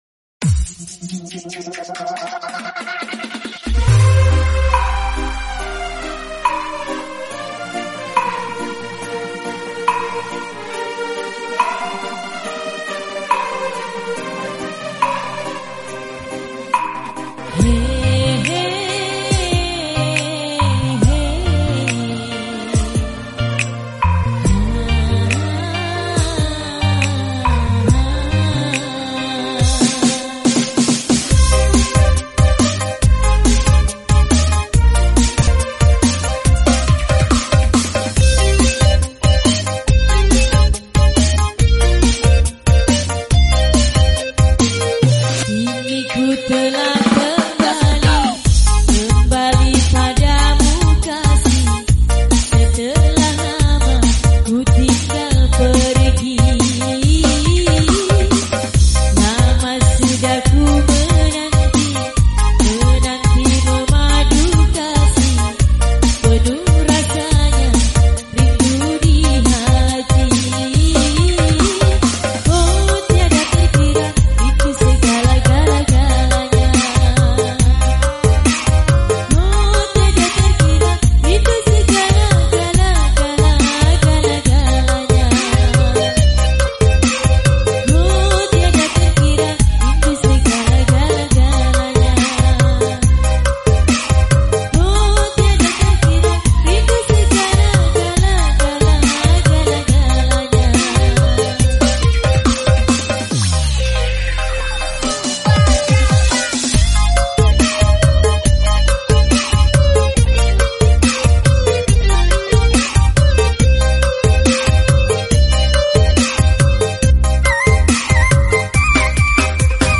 dangdut full bass